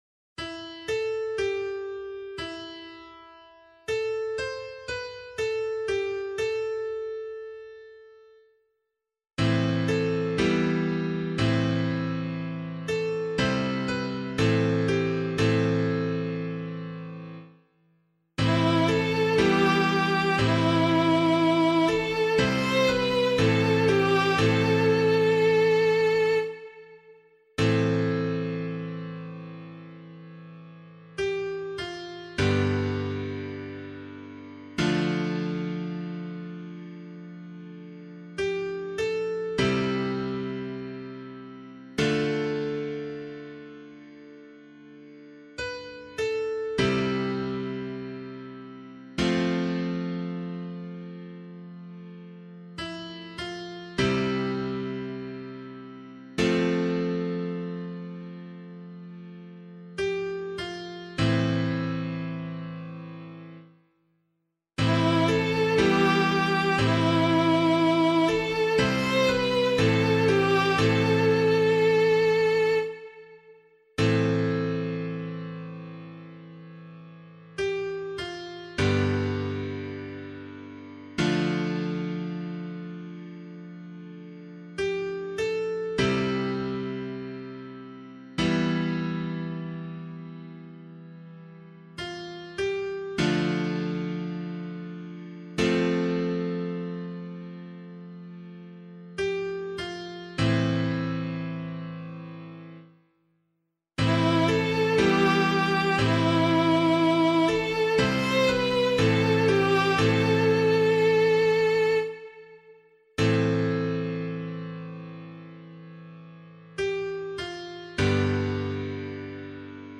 036 Ordinary Time 2 Psalm A [LiturgyShare 4 - Oz] - piano.mp3